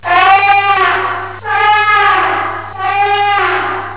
L'Éléphant (son cri est
Eleph.WAV